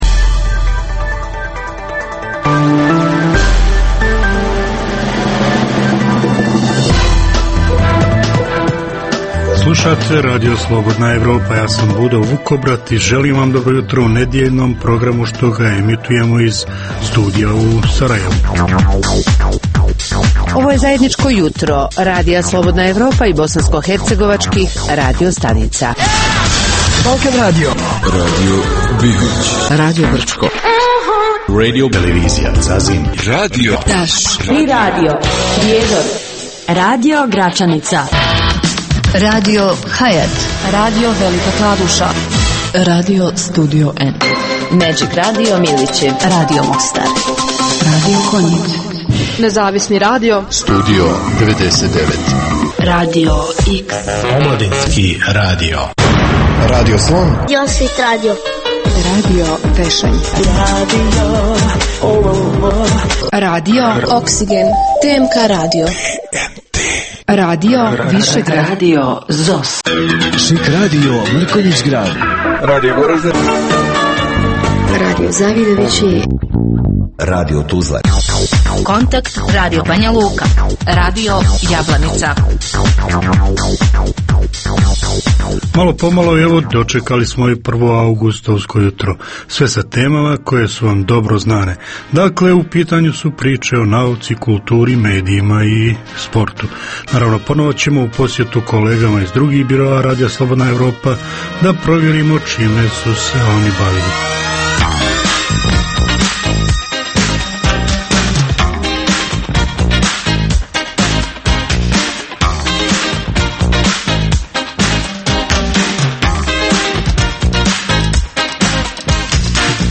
Jutarnji program namijenjen slušaocima u Bosni i Hercegovini. Sadrži intervju, te novosti iz svijeta nauke, medicine, visokih tehnologija, sporta, filma i muzike.